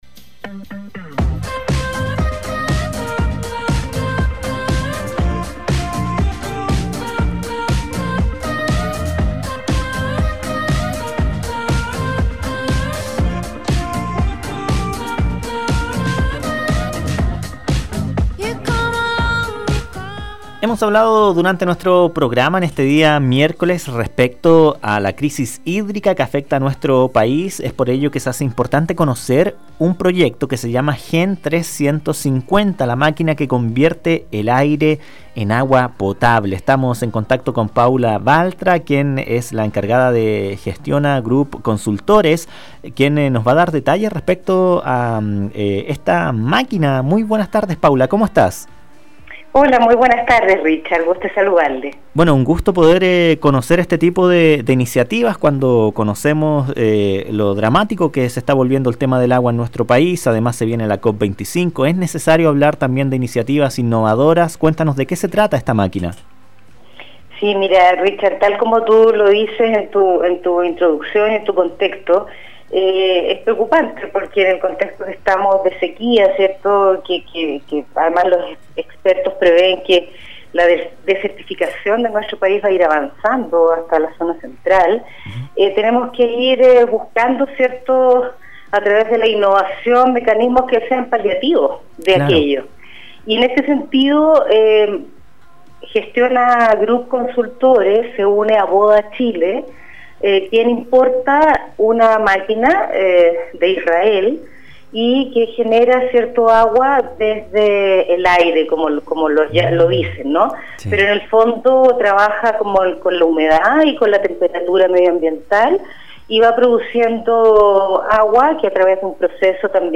ENTREVISTA-MAQUINA-AGUA-POTABLE.mp3